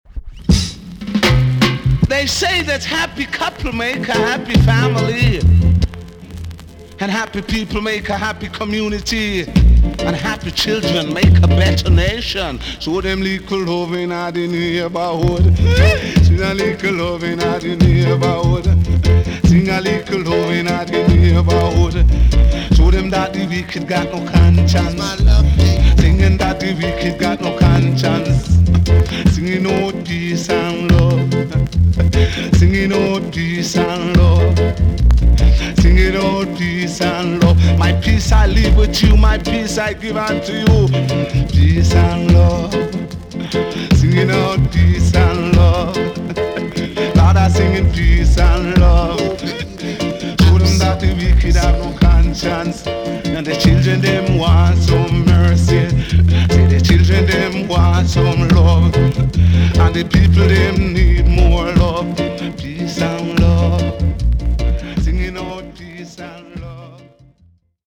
TOP >REGGAE & ROOTS
VG+ 軽いチリノイズがあります。
[ LABEL ] DEEJAY
NICE DEEJAY TUNE!!